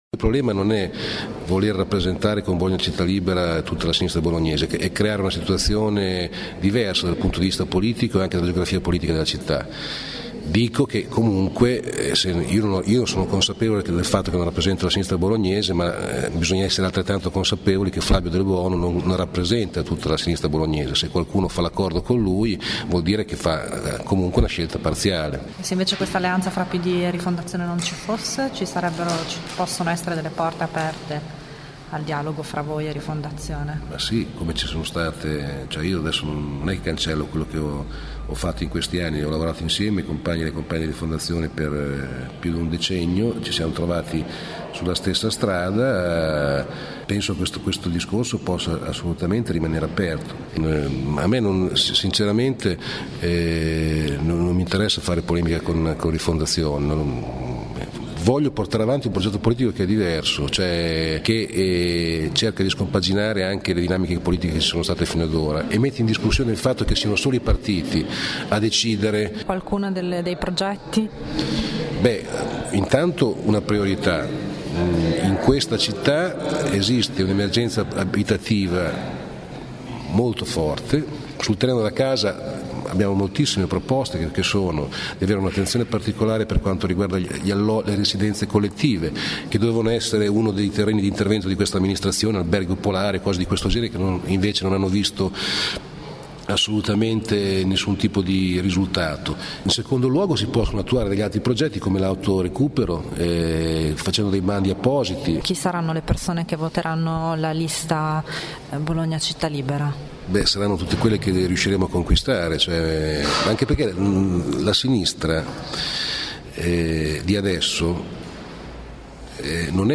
Si è dimesso oggi ufficialmente dal ruolo di presidente della commissione Casa, Sanita’ e Politiche sociali del Comune di Bologna, Valerio Monteventi, indipendente del Prc e leader – insieme a Franco “Bifo” Berardi – della lista Bologna Città Libera, di cui dovrebbe essere con ogni probabilità il candidato sindaco alle prossime amministrative. Sentiamolo in questa intervista